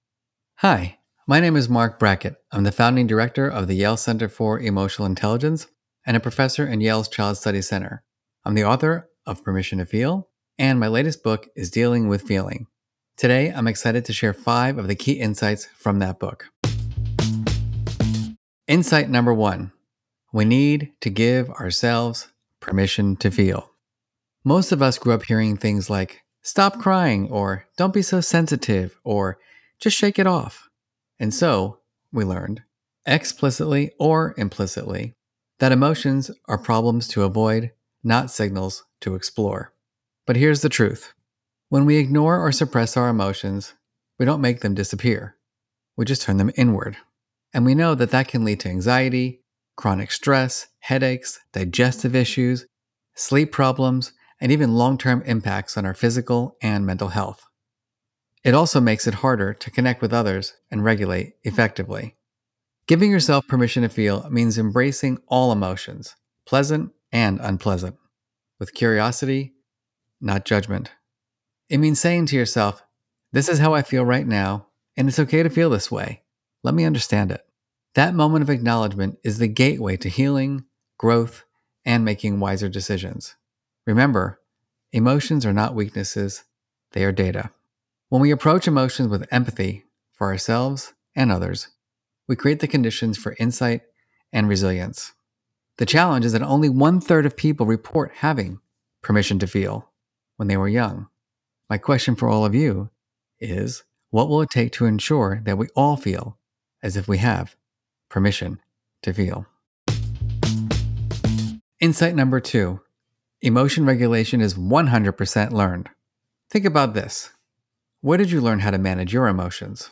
Listen to the audio version of this Book Bite—read by Marc himself—below, or in the Next Big Idea App.